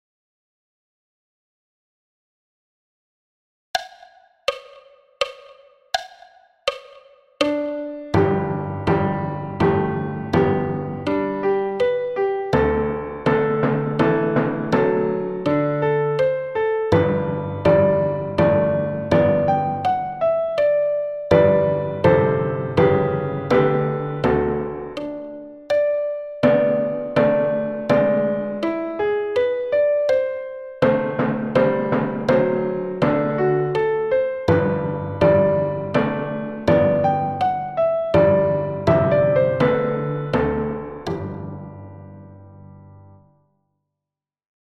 Menuet Haydn – tutti 82 bpm